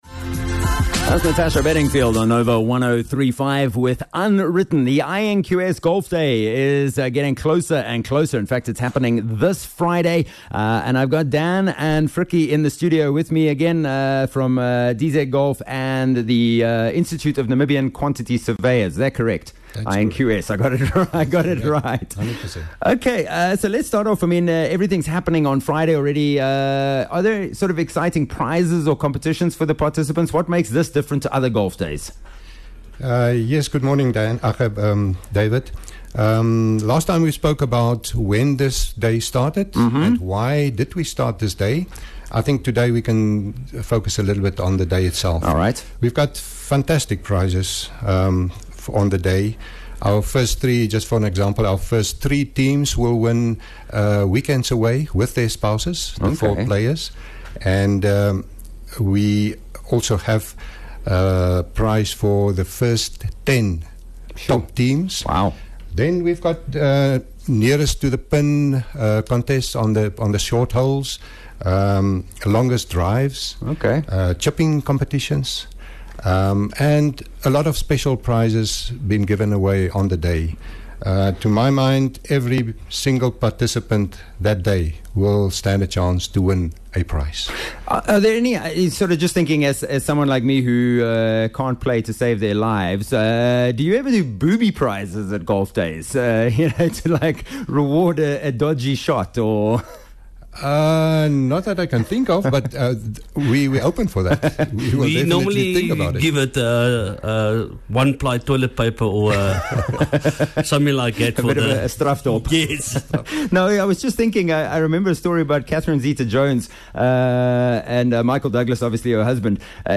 9 Oct INQS Golf Day - Interview 2